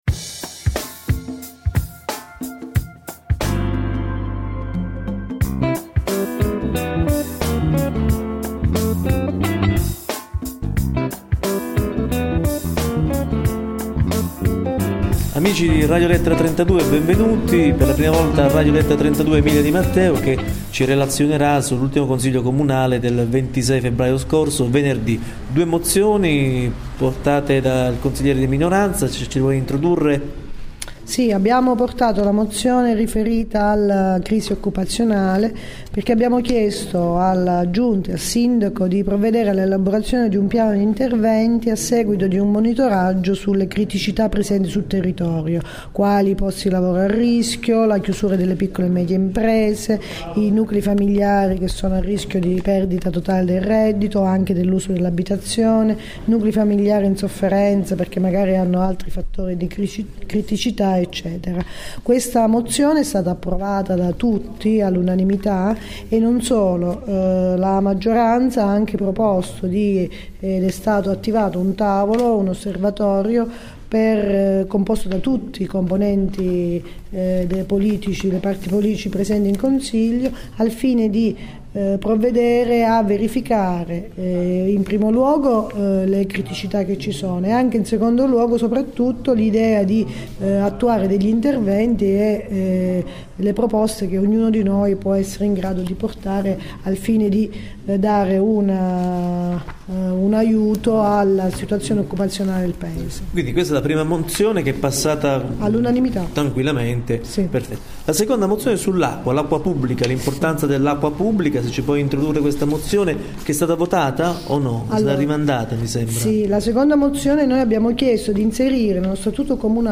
Una breve intervista di cinque minuti al consigliere di minoranza Emilia Di Matteo, che ha presentato due importanti mozioni nell'ultimo consiglio comunale del 26 febbraio scorso. La prima riguarda un monitoraggio dell' occupazione locale.